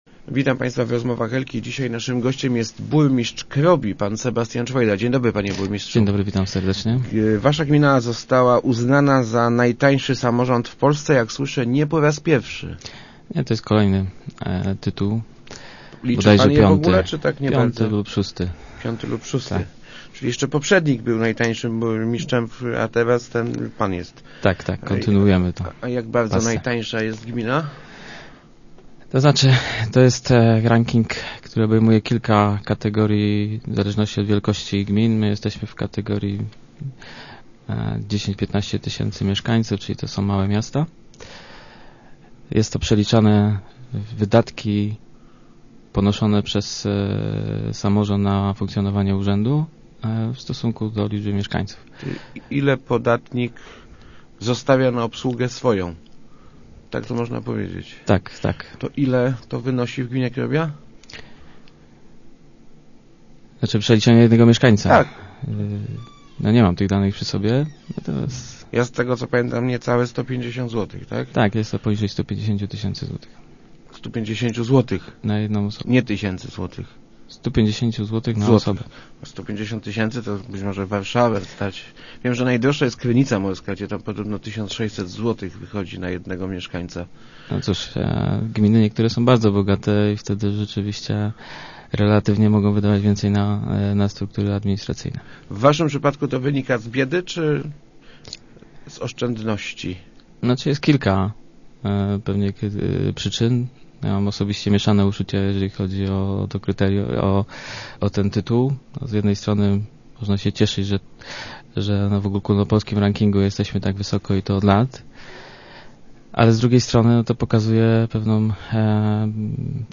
sczwojda.jpgTytuł najtańszego samorządu w Polsce przyjmujemy z mieszanymi uczuciami – mówił w Rozmowach Elki burmistrz Krobi Sebastian Czwojda.